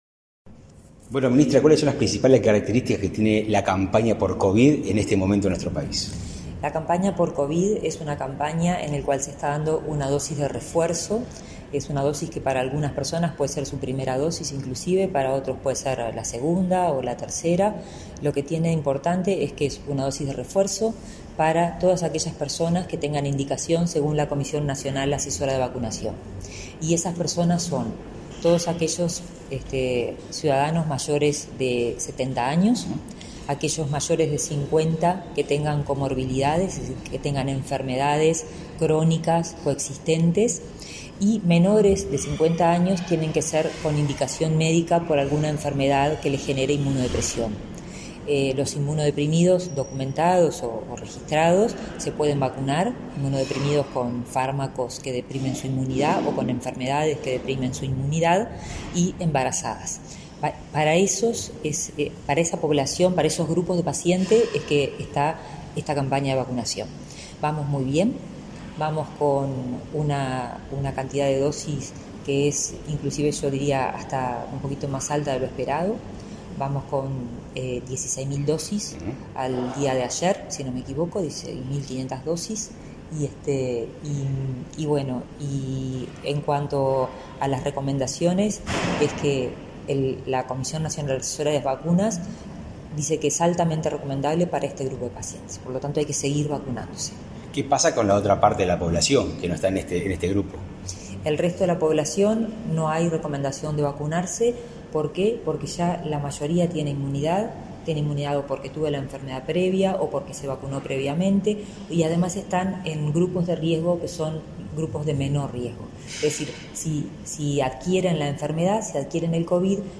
Entrevista a la ministra de Salud Pública, Karina Rando